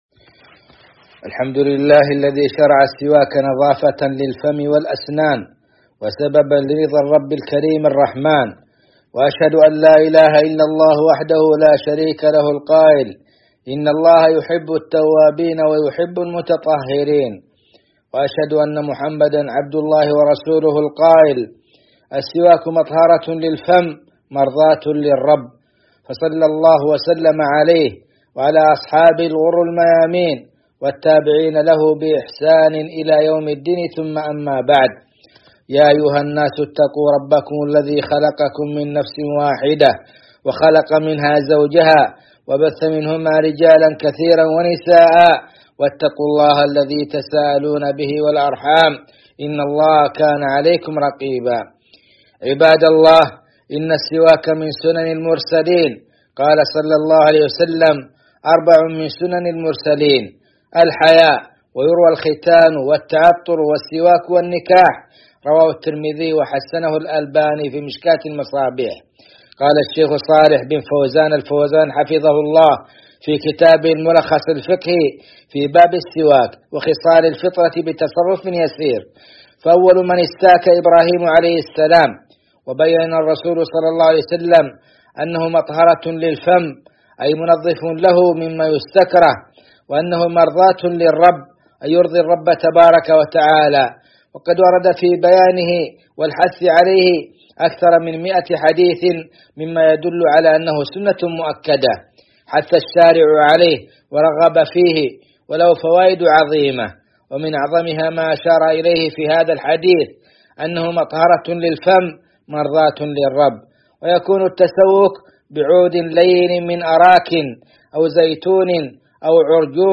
خطبة
خطب الجمعة والأعياد